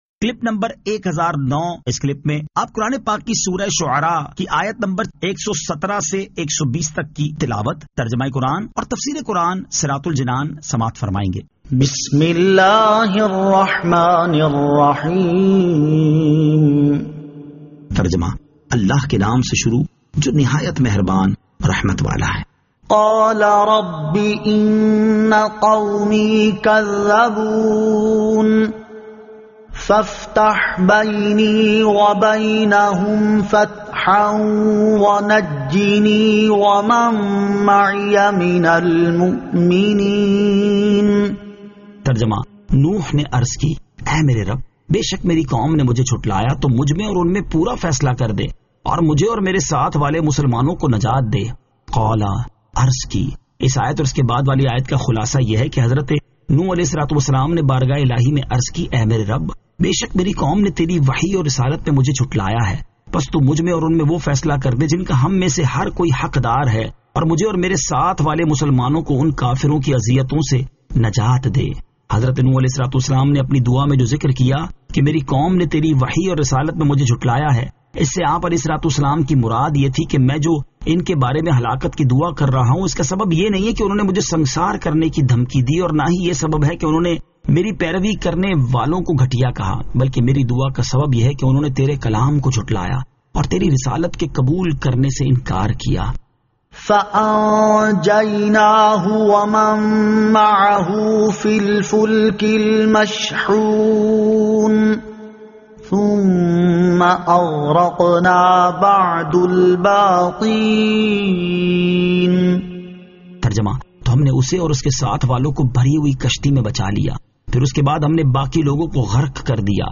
Surah Ash-Shu'ara 117 To 120 Tilawat , Tarjama , Tafseer